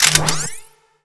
sci-fi_weapon_reload_01.wav